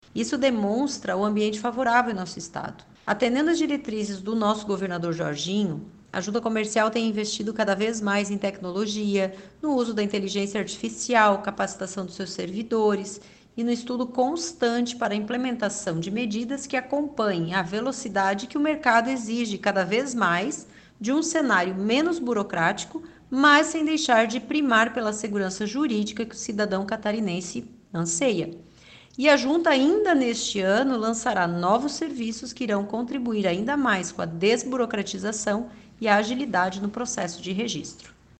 A presidente em exercício da Jucesc, Fabiana Everling, destaca que o mercado atual exige cada vez mais um cenário menos burocrático sem deixar de primar pela segurança jurídica que o cidadão catarinense anseia:
SECOM-Sonora-presidente-da-Jucesc-em-exercicio.mp3